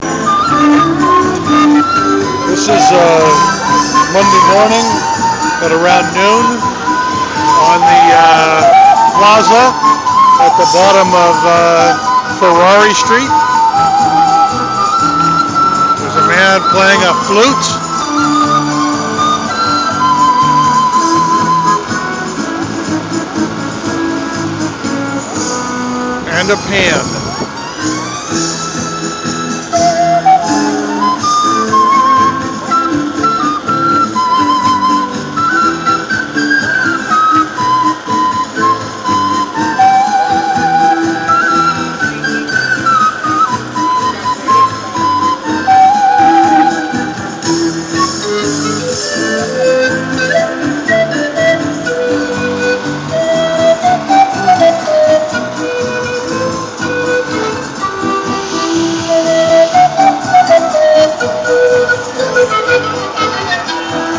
Valparaiso flute music
valparaiso-flute-music.wav